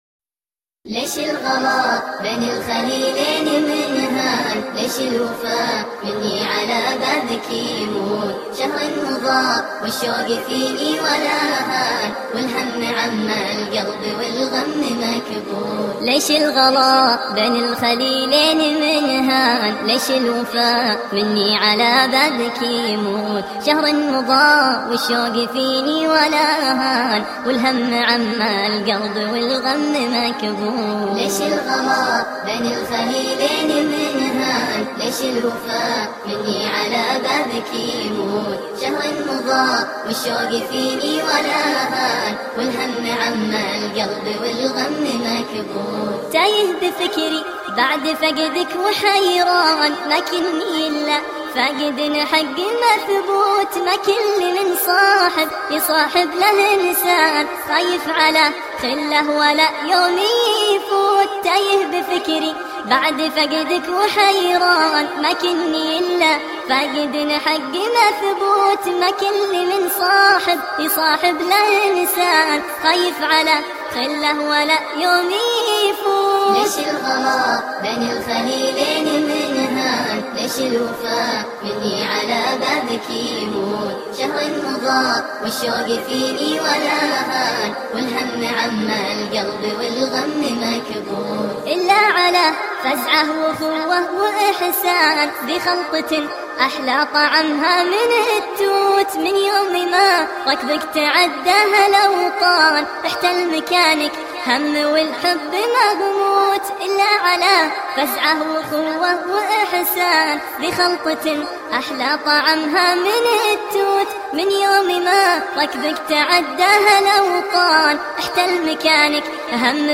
انشوده